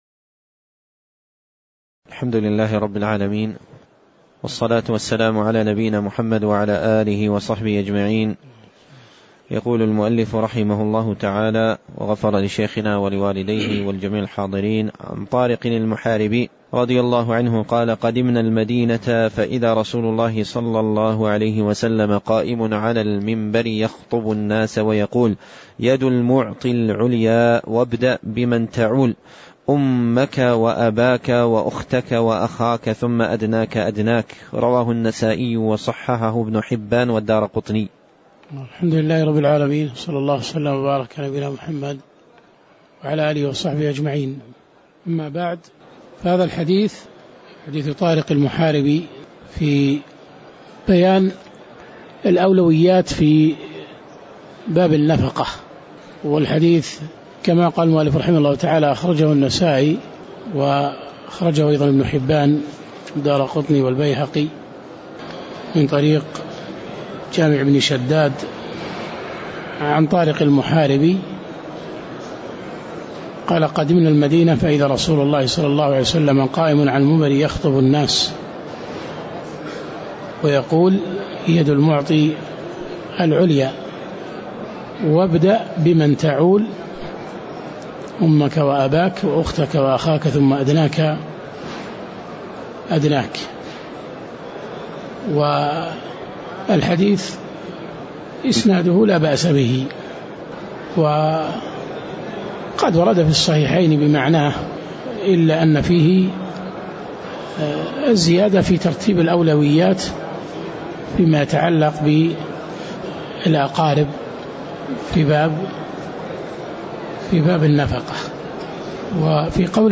تاريخ النشر ١٧ ربيع الأول ١٤٣٩ هـ المكان: المسجد النبوي الشيخ